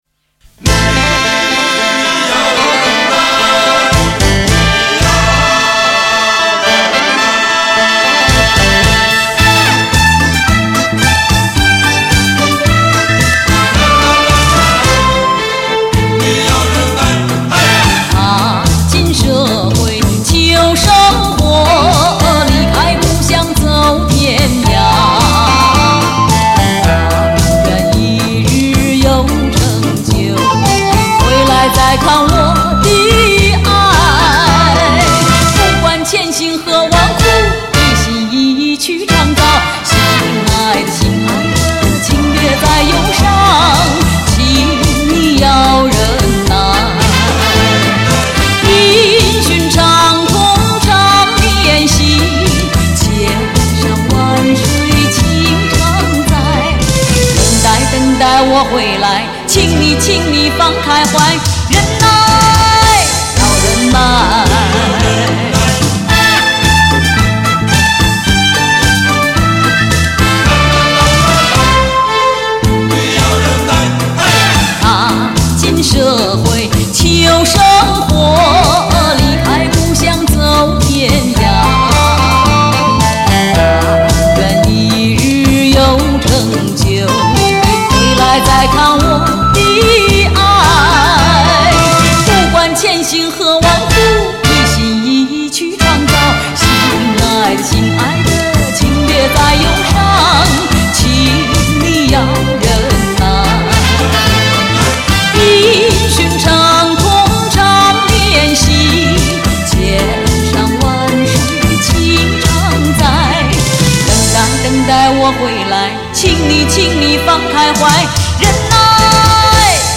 本CD母带经美国太平洋微音公司HDCD贰型处理器处理。
她的歌声高昂激越、委婉深沉、风趣诙谐，还透着质朴，犹如她的为人。